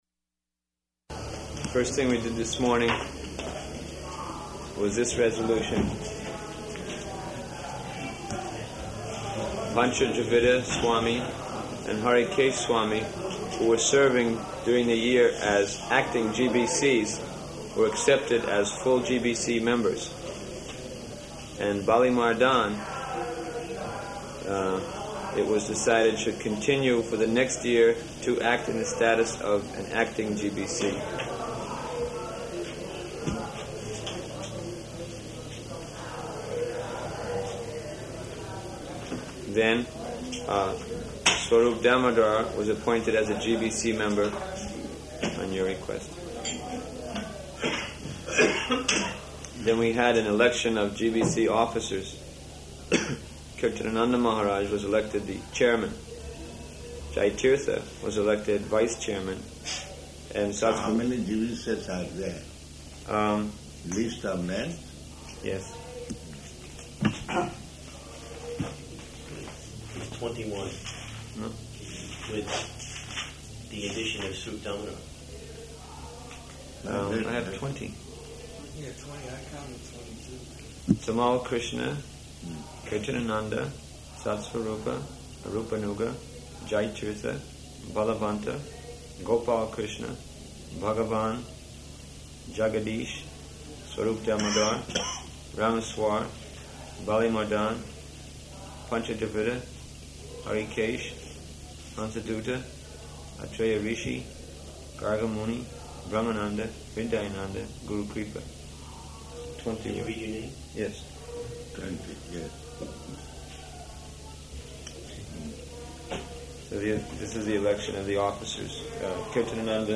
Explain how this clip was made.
Location: Māyāpur